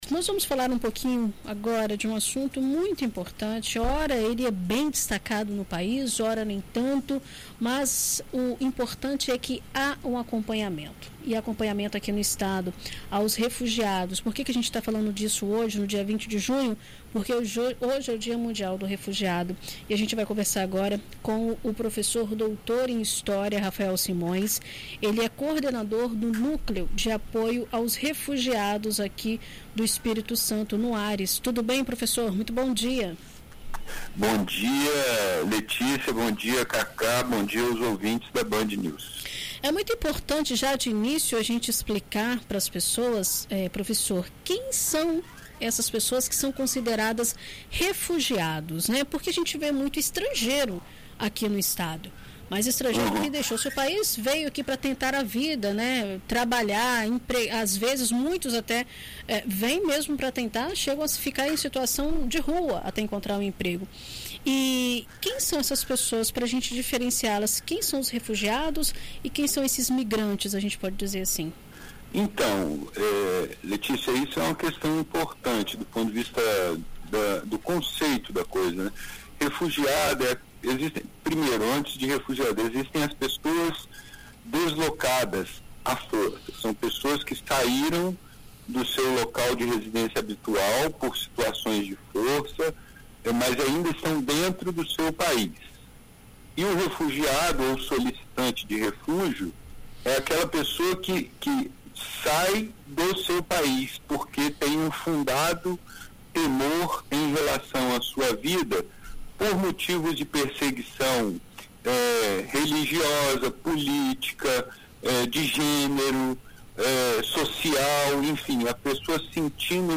Em uma entrevista concedida à BandNews FM ES nesta terça-feira (20)